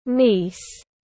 Cháu gái tiếng anh gọi là niece, phiên âm tiếng anh đọc là /niːs/.
Niece /niːs/